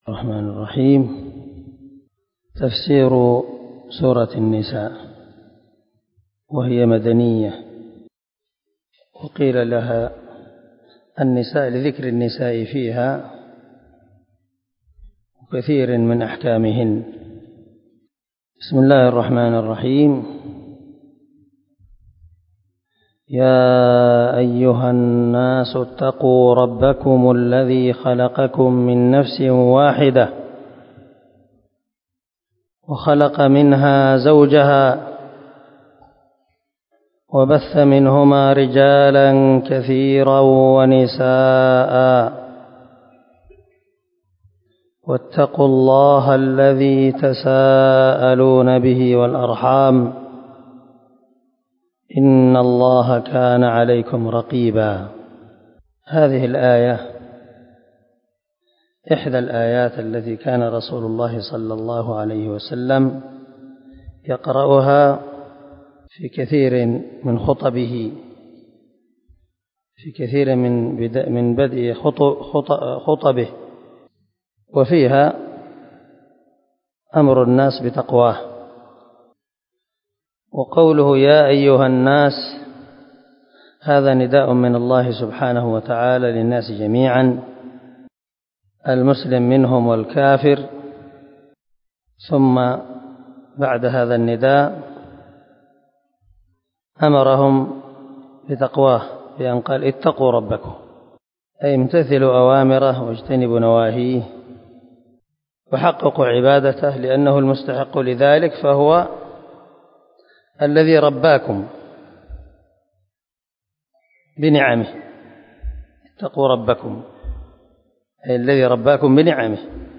الدرس
دار الحديث- المَحاوِلة- الصبيحة.